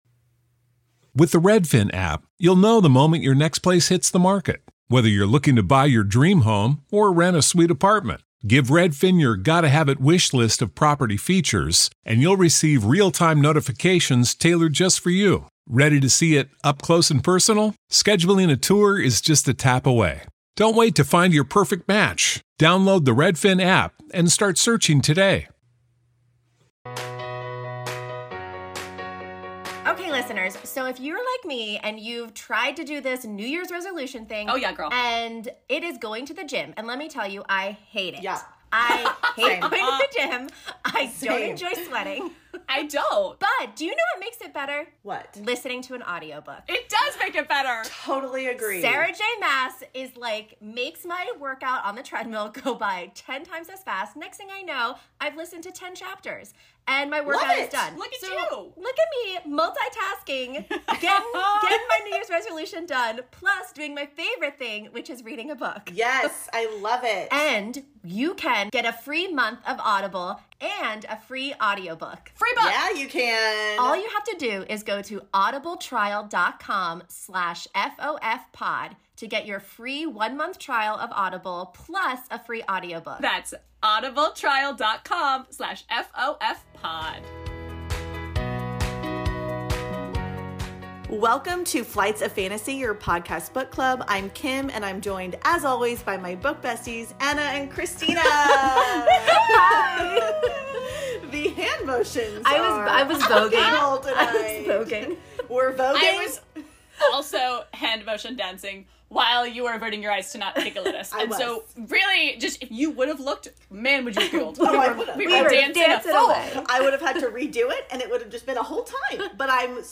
giggle their way through their latest KU reads.